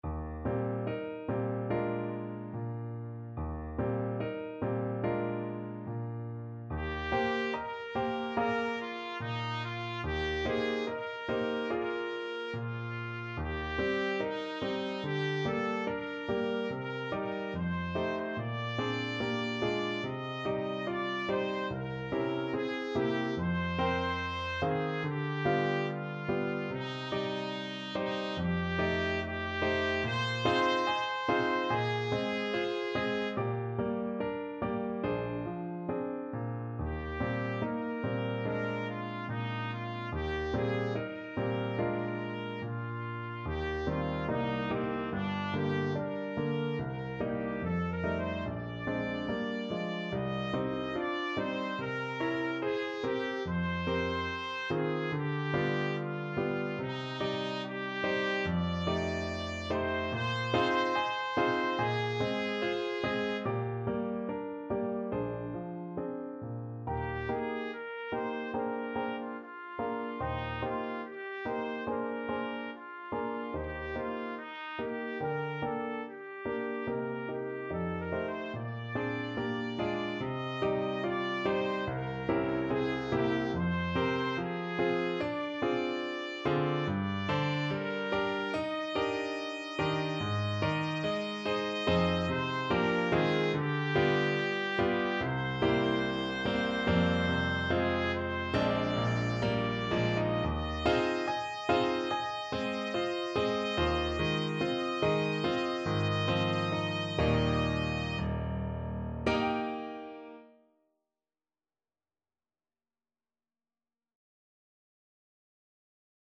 ~ = 72 In moderate time
4/4 (View more 4/4 Music)
Classical (View more Classical Trumpet Music)